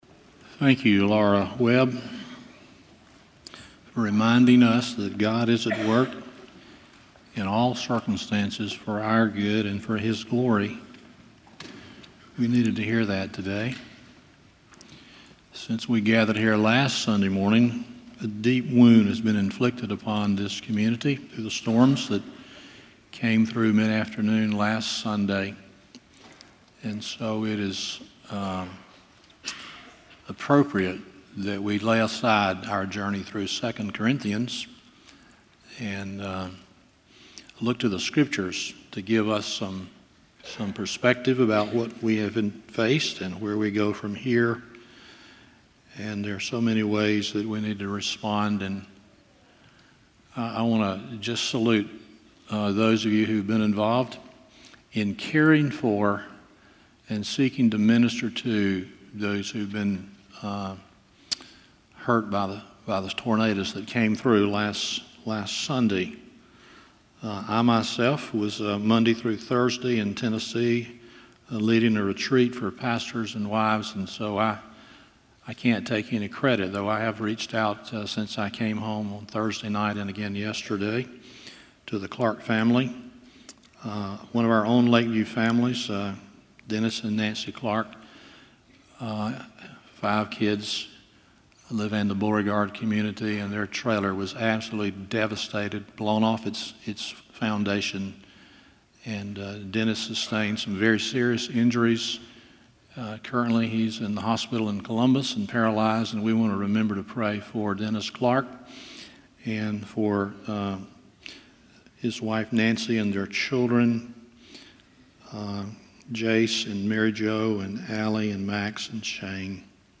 Stand Alone Sermons
Service Type: Sunday Morning